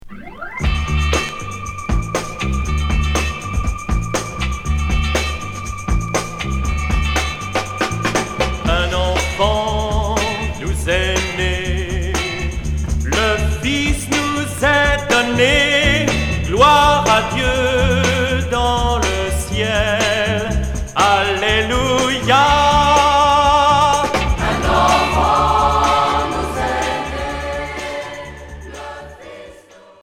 Jerk catholique